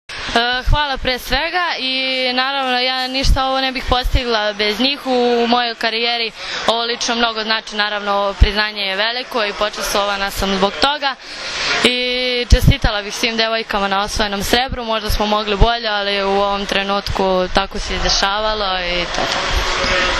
Na beogradskom aerodromu “Nikola Tesla”, srebrne juniorke su sa cvećem dočekali predstavnici Odbojkaškog saveza Srbije.
IZJAVA